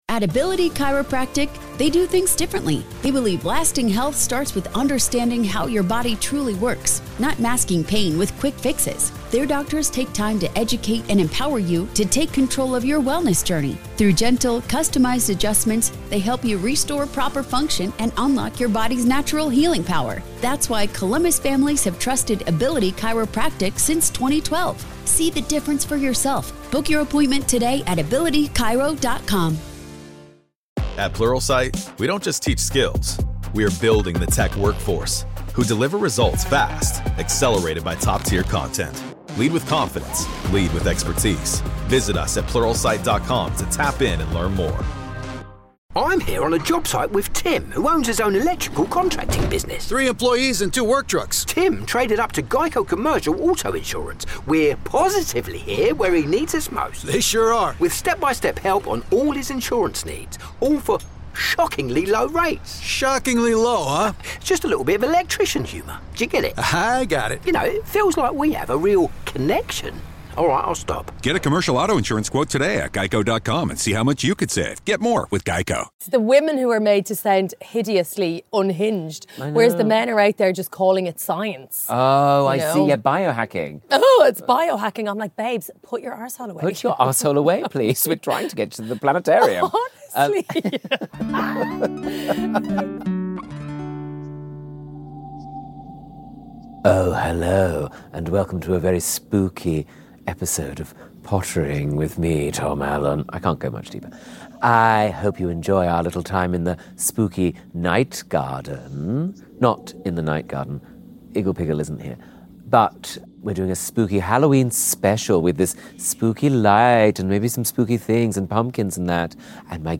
On Pottering this week, things take a splendidly spooky turn as we celebrate Halloween in the garden with broadcaster, author and bewitching presence Angela Scanlon!
As darkness falls, our hauntingly horticultural conversation covers crystals, carveries and Angela's bumper crop.